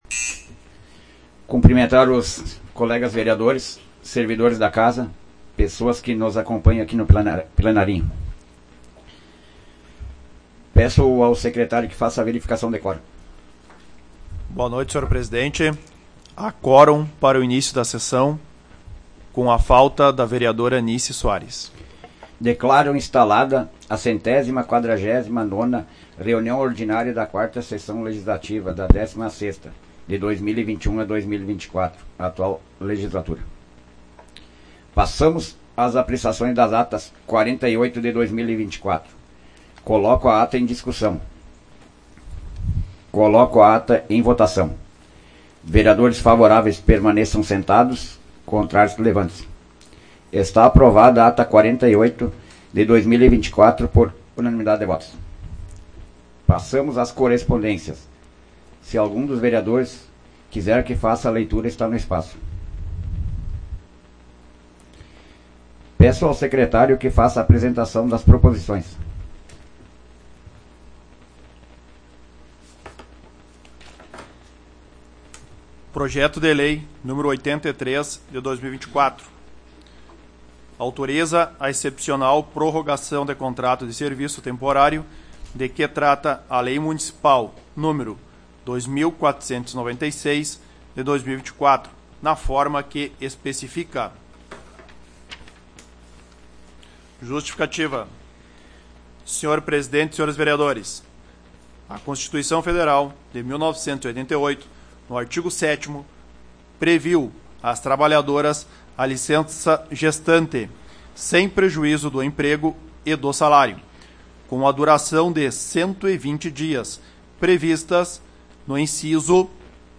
Áudio da 149ª Sessão Plenária Ordinária da 16ª Legislatura, de 16 de setembro de 2024